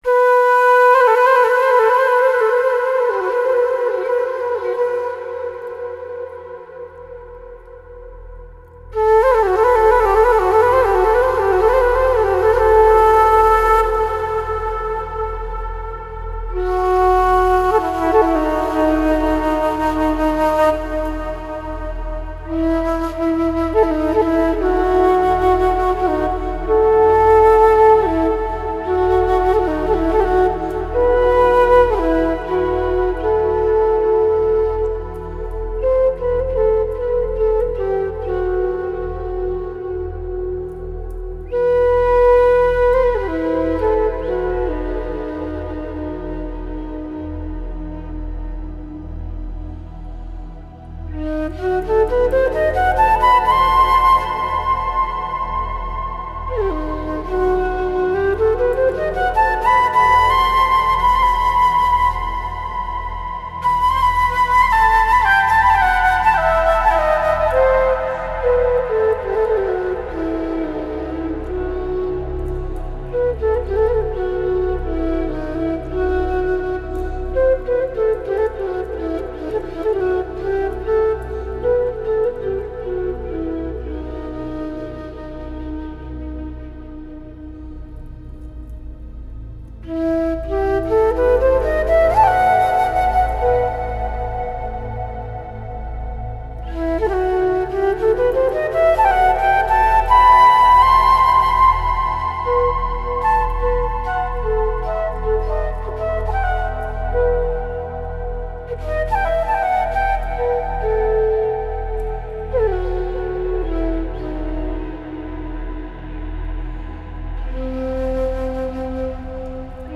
Genre: Deep Ambient.